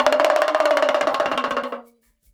80 PERC 04.wav